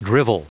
Prononciation du mot drivel en anglais (fichier audio)
drivel.wav